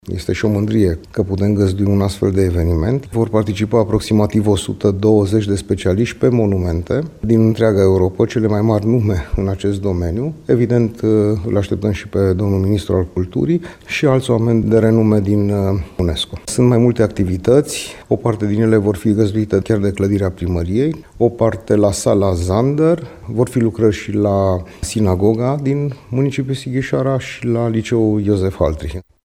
Primarul Ovidiu Mălăncrăvean: